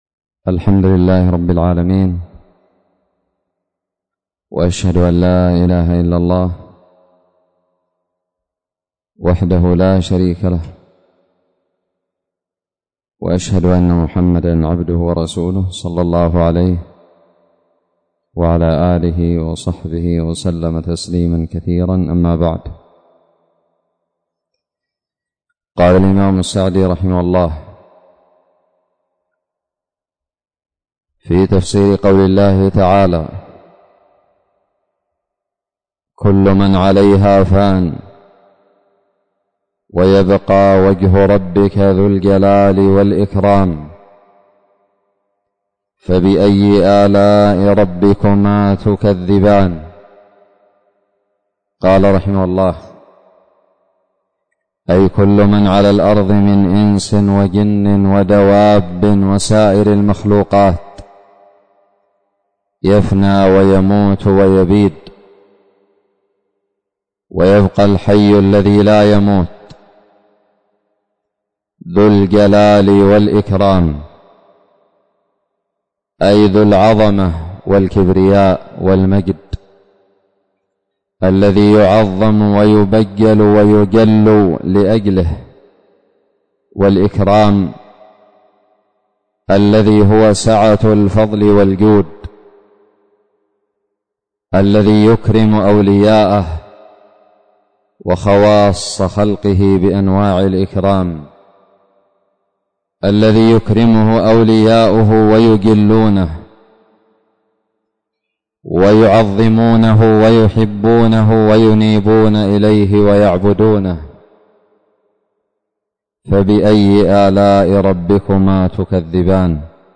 الدرس الثالث من تفسير سورة الرحمن
ألقيت بدار الحديث السلفية للعلوم الشرعية بالضالع